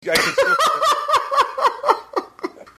Laugh Cackle